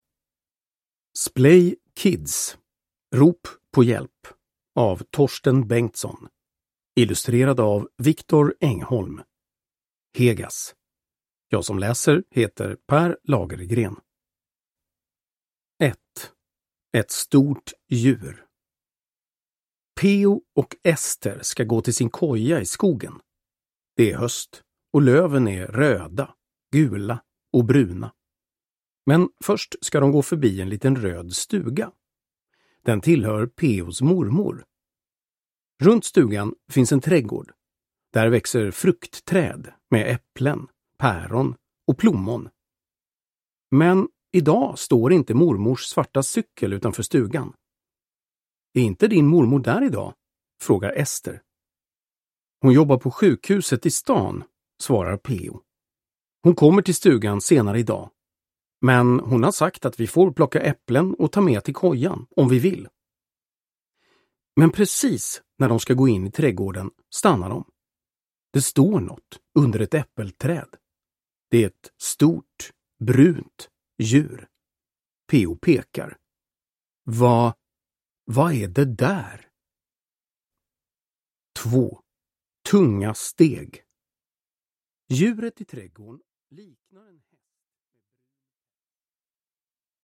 Rop på hjälp! (ljudbok) av Torsten Bengtsson